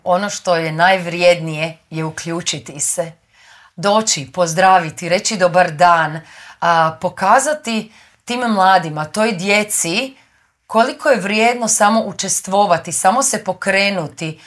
Intervjuu Media servisa